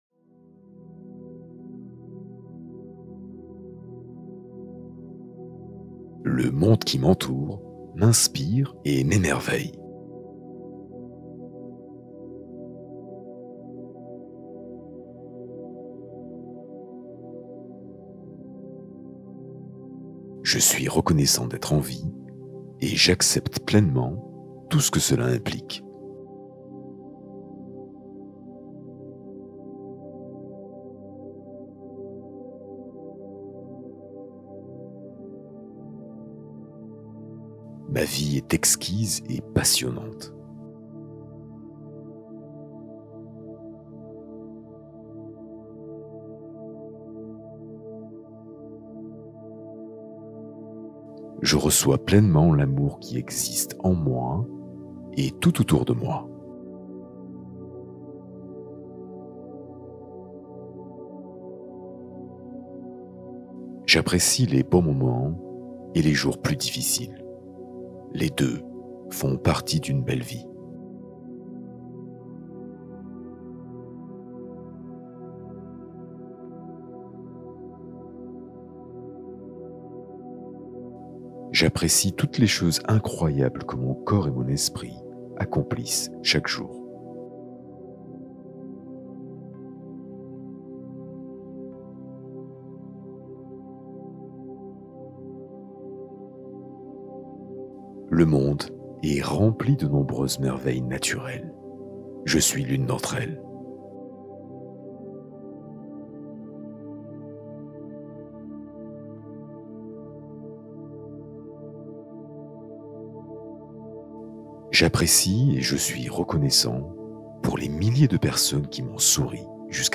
Hypnose pour développer une relation plus douce à soi-même